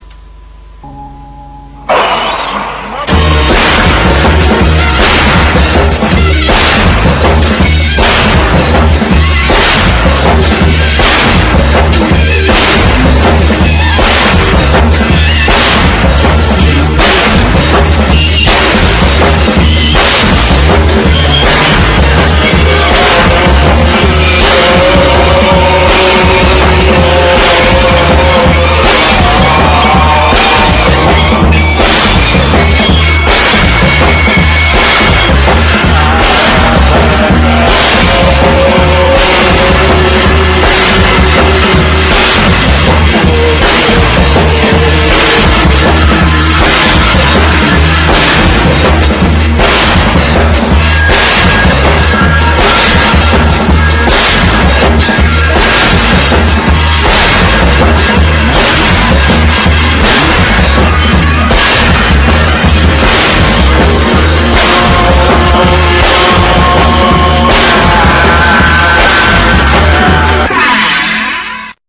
CYBERPUNK